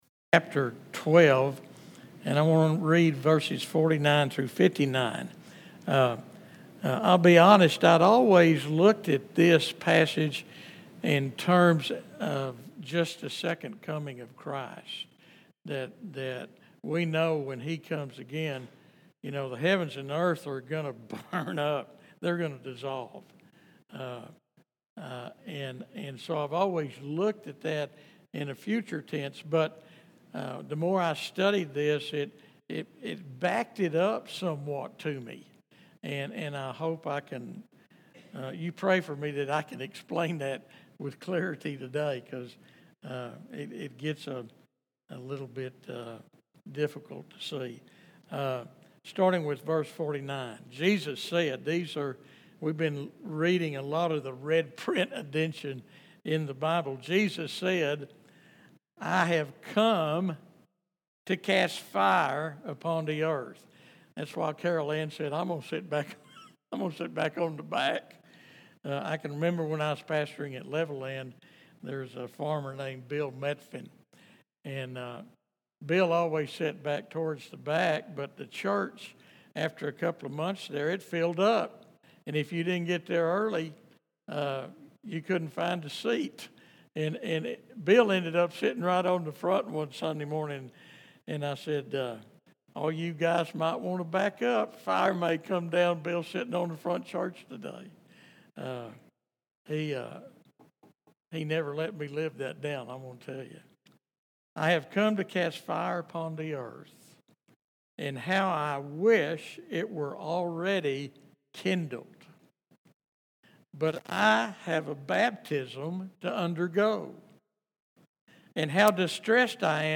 Join us for this week's sermon exploring Jesus' words about casting fire upon the earth and the divine judgment that would fall upon Him at the cross. This message reveals the profound truth that Christ bore God's wrath for our sins, paying a debt He did not owe so we could go free.